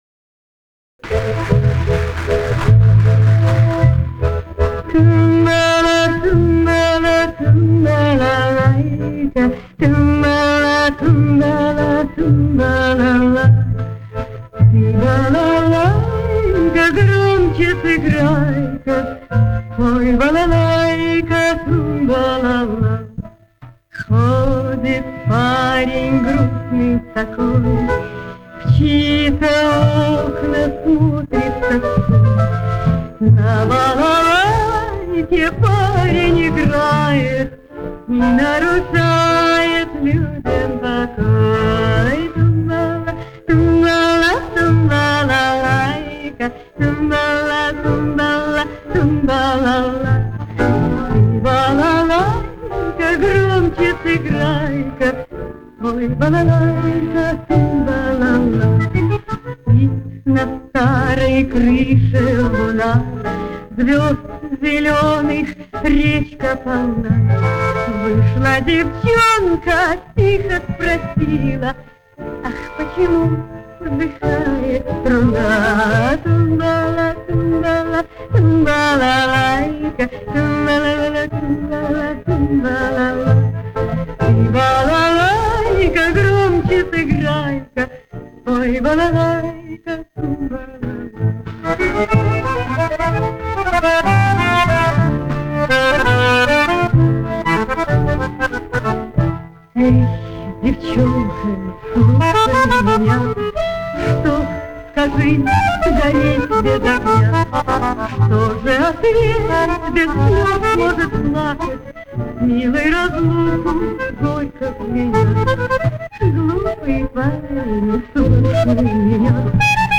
Тоже была записана, но на пластинки не попала.
Это оригинал с пленки.